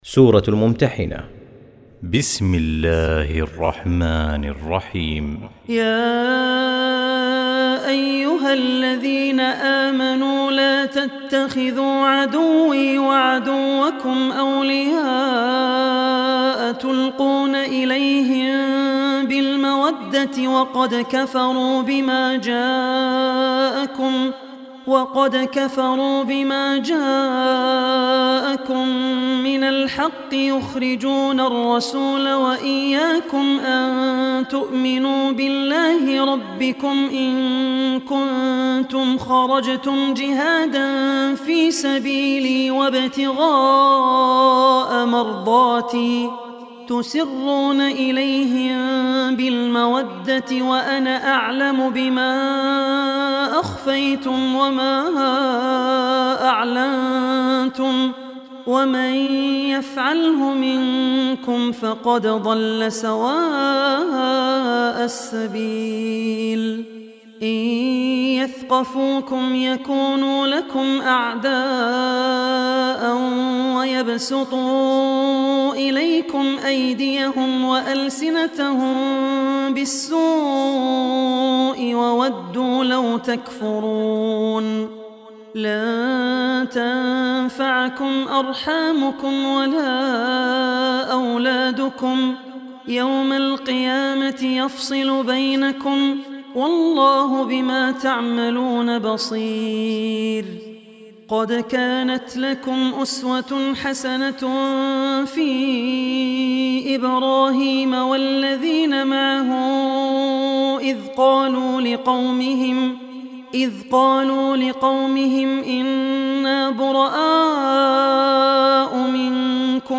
Surah Mumtahina Recitation by Abdur Rehman Al Ossi
Surah Al-Mumtahina audio recitation.